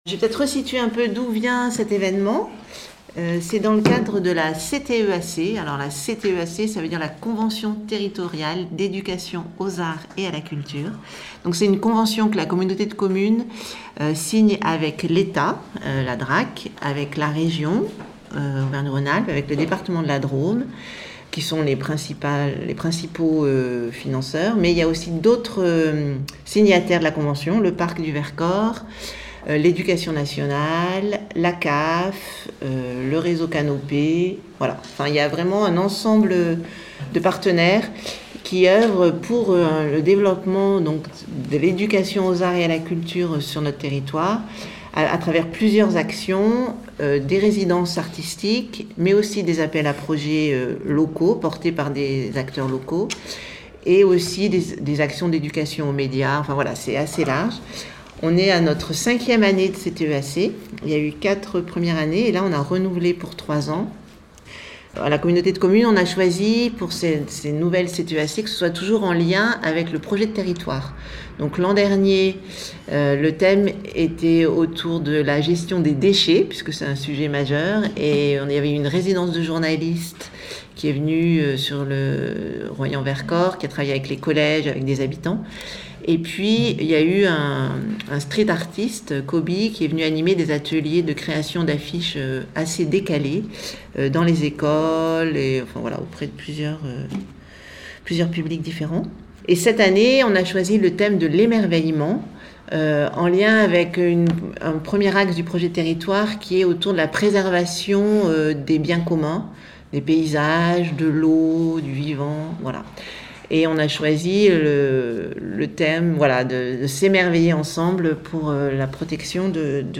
Afin de préciser au mieux l’intention de cette journée, Radio Royans Vercors a capté le 13 mai 2024 la conférence de presse de présentation.